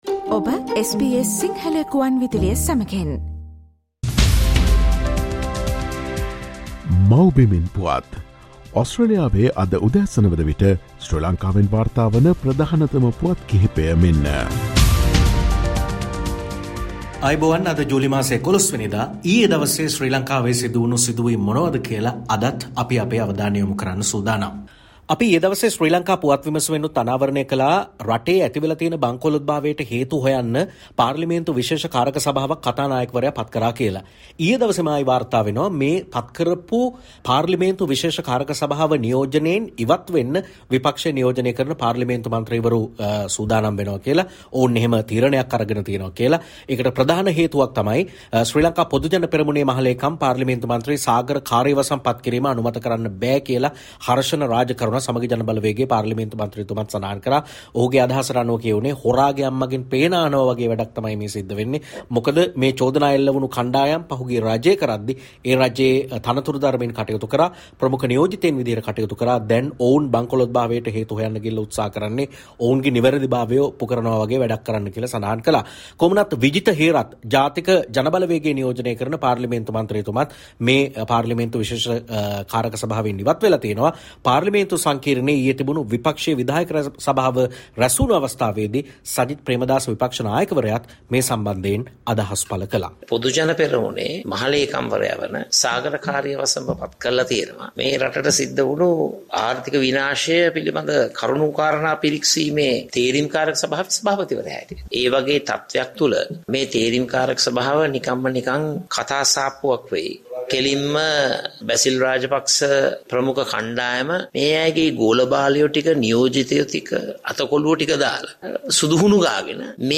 Featuring the latest news reported from Sri Lanka - Mawbimen Puwath්